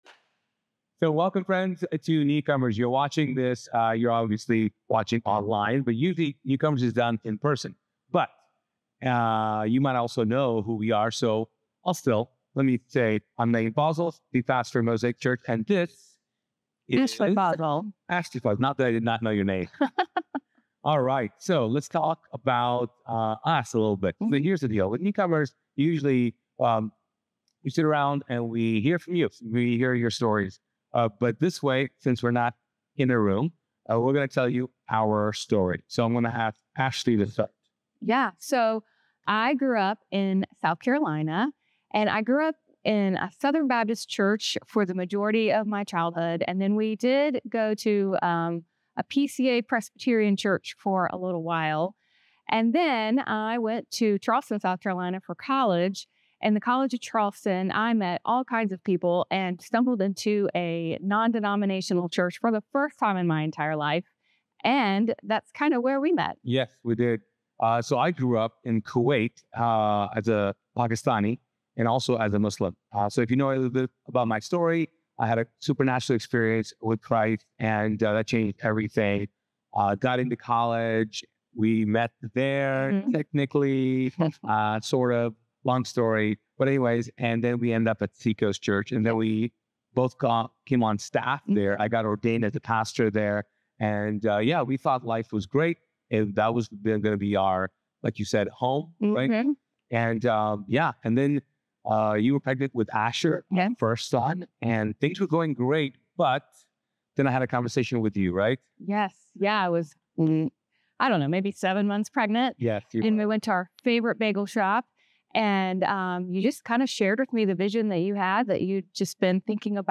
This conversation